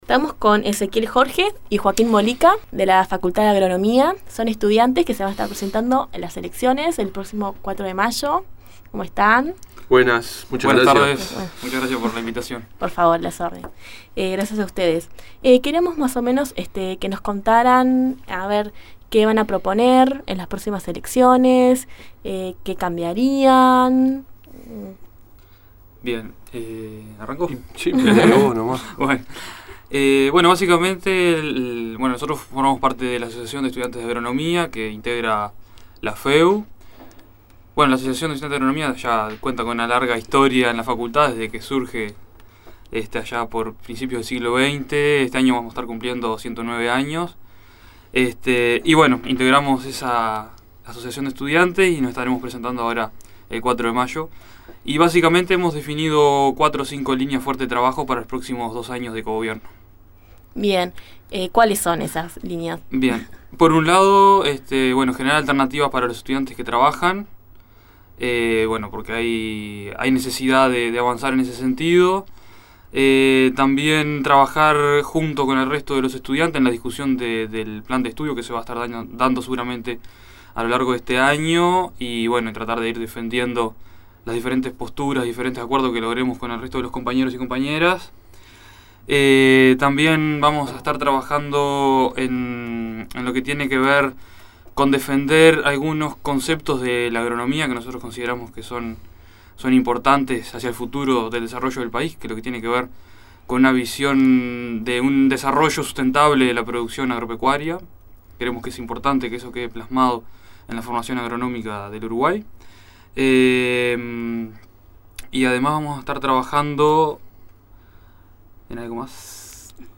Entrevista-FEUU-Agronomia.mp3